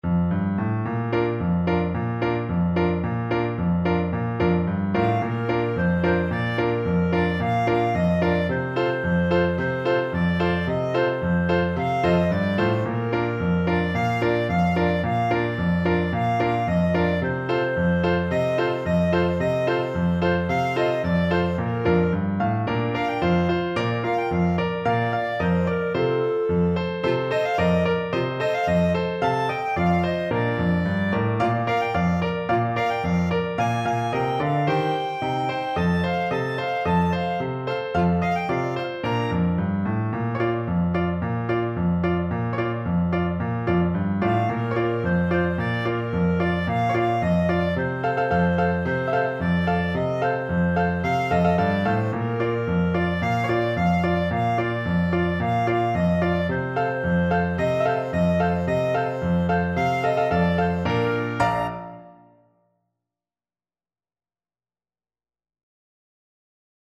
Clarinet
Bb major (Sounding Pitch) C major (Clarinet in Bb) (View more Bb major Music for Clarinet )
4/4 (View more 4/4 Music)
Playfully =c.110
Classical (View more Classical Clarinet Music)